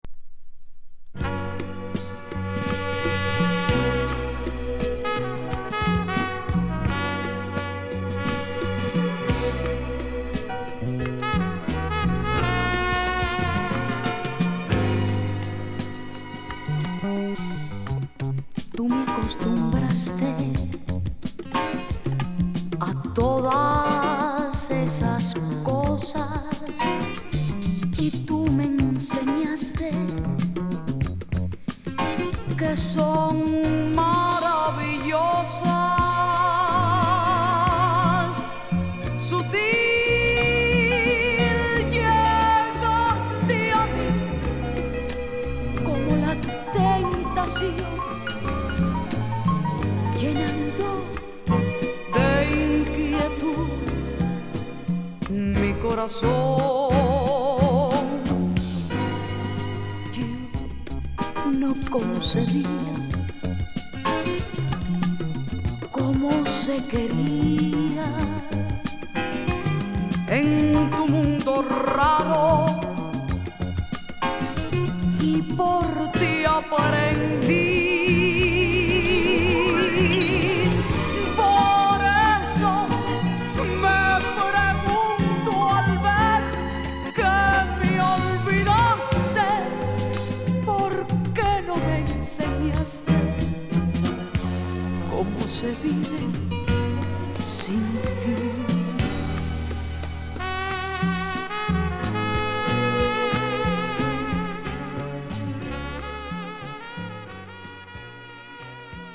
1970年から活動を始めたキューバの女性シンガー
往年のボレロ/フィーリンの名曲カバーも収録